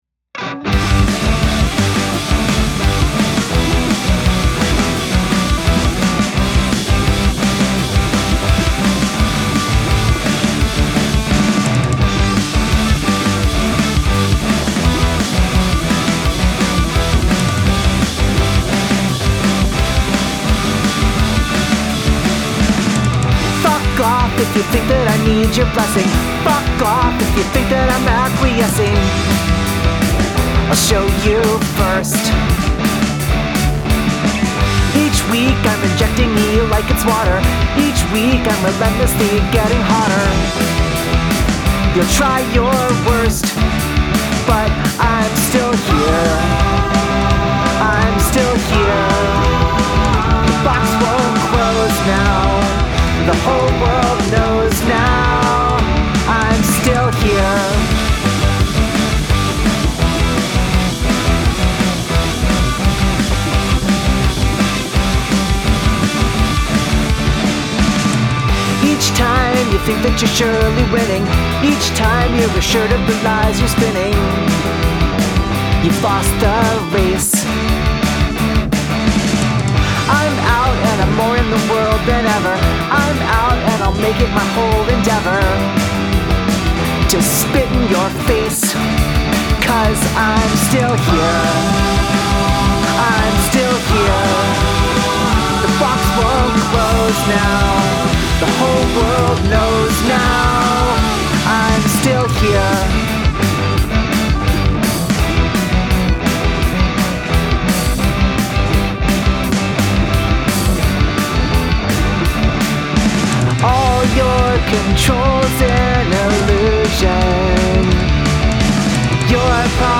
Catchy riffs and punchy lyrics.
This has such an awesome pop punk 2000s sound.